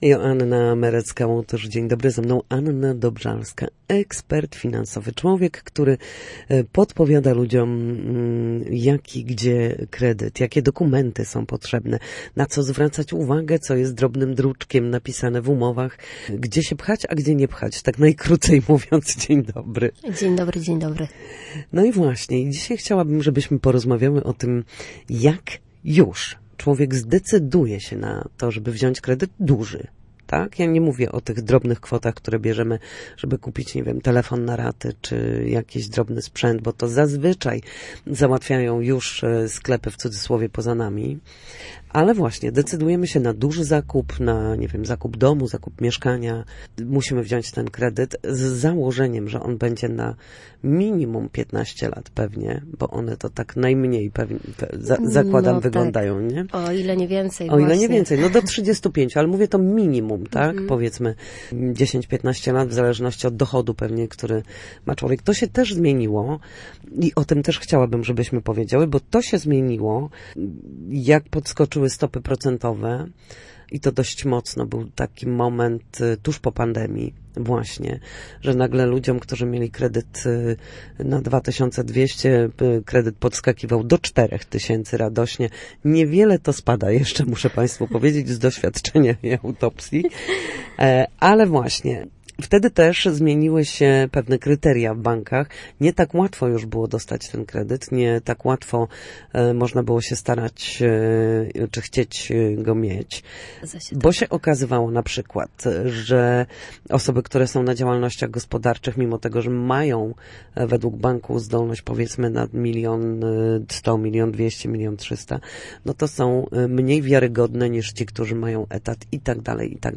Jak zwiększyć swoje szanse na uzyskanie kredytu hipotecznego? Rozmowa z ekspertką